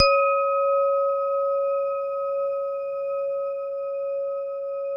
WHINE  C#3-R.wav